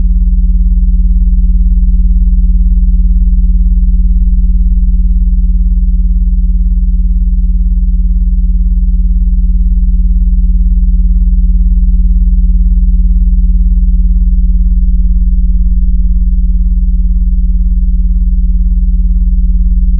I am currently standing in the mechanical penthouse of a 40-story office tower in Chicago.
The power is cut. The air handlers are silent. The only sound is the wind whistling through a cracked seal on the 32nd floor—a low, mournful note that I’ve clocked at exactly 42Hz. It sounds like someone blowing over the top of a massive, glass bottle.
I recorded the room tone here. I wanted to capture the sound of a building that has lost its purpose.
The 42Hz hum isn’t a fan. It’s the resonant frequency of the structure itself. It’s the sound of the building shivering.
(The file is labeled Nebraska from a previous draft, but the audio is pure Chicago ghost signal.)